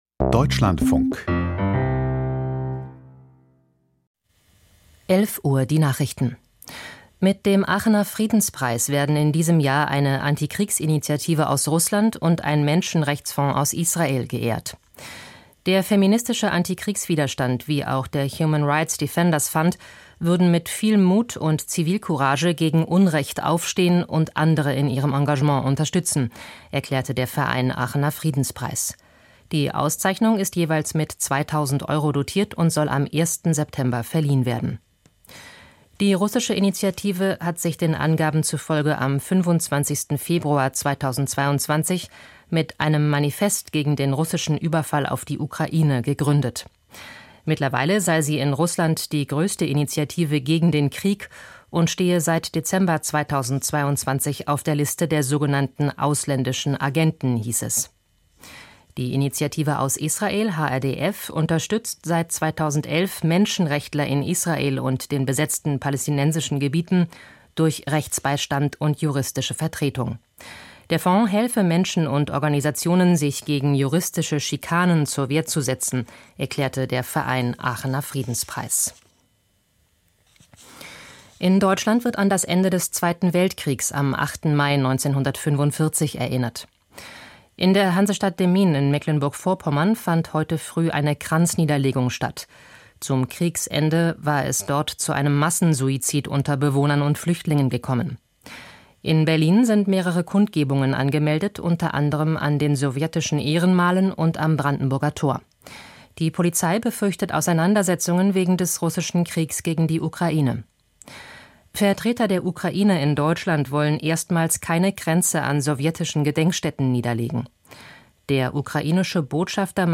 Nachrichten vom 08.05.2023, 11:00 Uhr